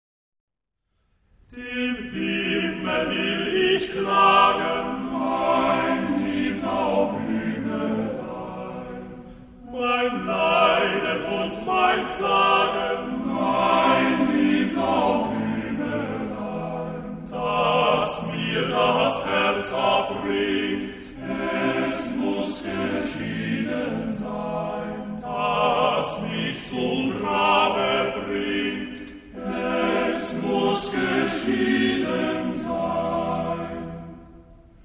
Genre-Style-Forme : Romantique ; Profane ; Populaire
Type de choeur : TTBB  (4 voix égales d'hommes )
Solistes : Ténors (2) / Basses (2)  (4 soliste(s))
Tonalité : mi mineur